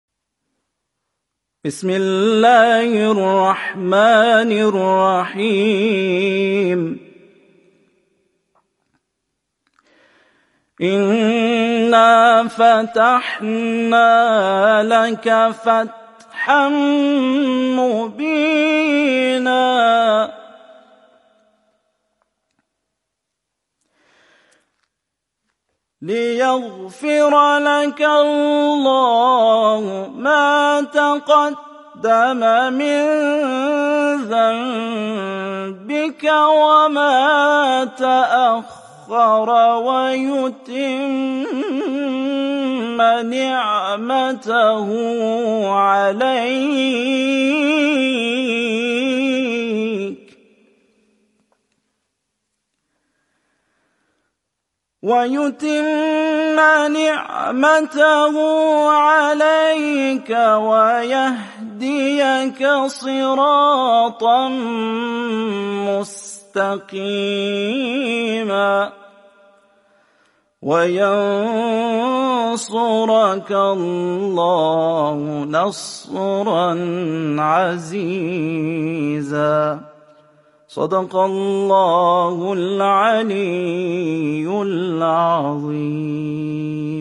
برچسب ها: آموزش قرآن ، قاری قرآن ، بوستان قرآن